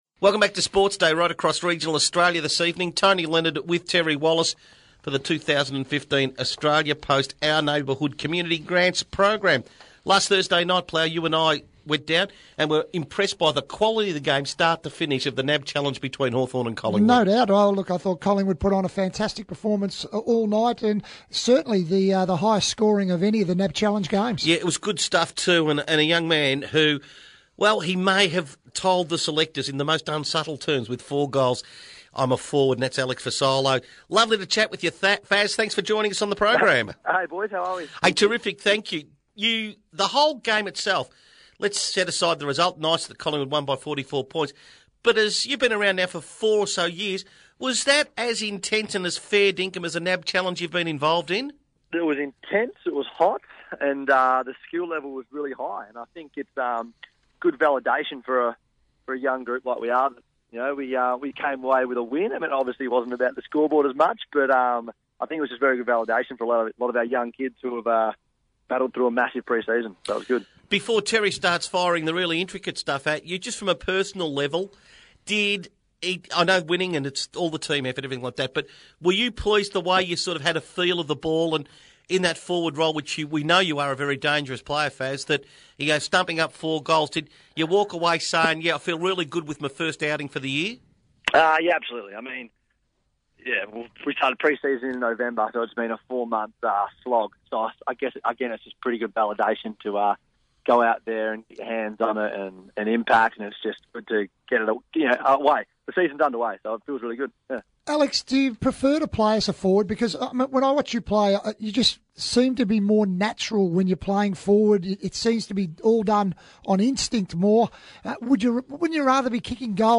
Radio: Alex Fasolo on SportsDay